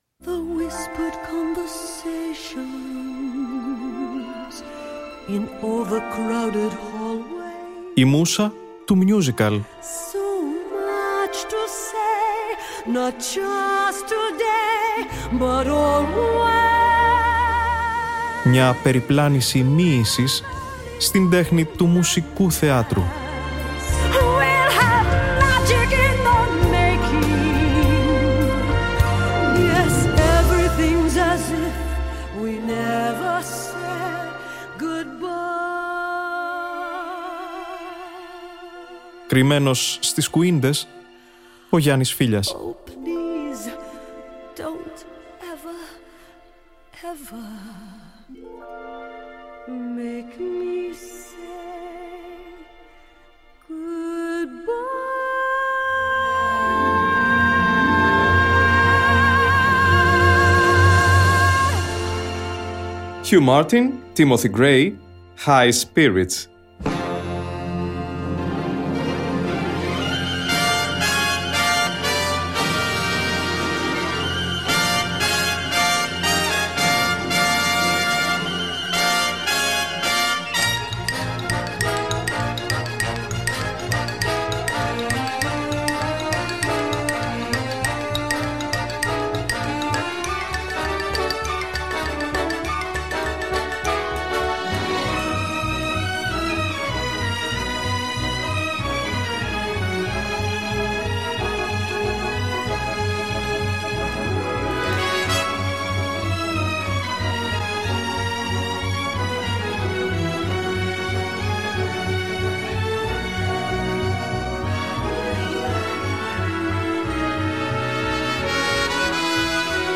Ακούστε στην εκπομπή της Παρασκευής 08.11.2024 ένα υπέροχο «μαύρο» μιούζικαλ.